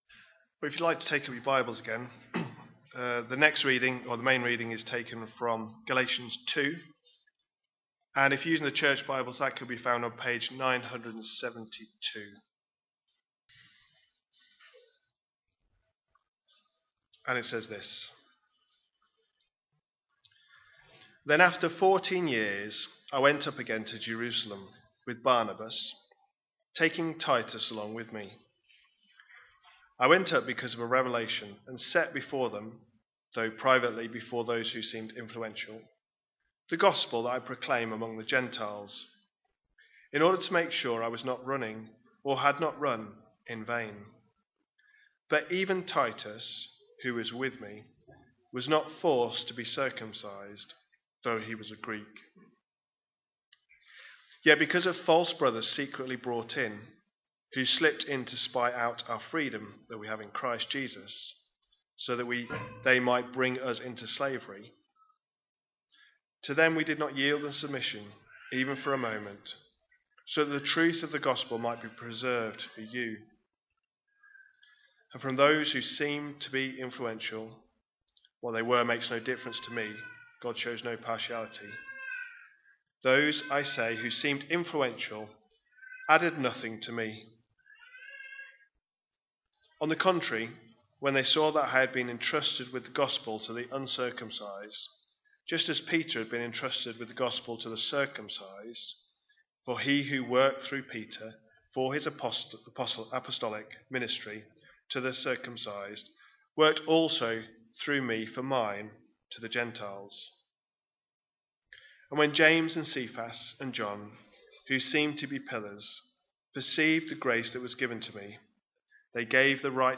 A sermon preached on 8th June, 2025, as part of our Galatians series.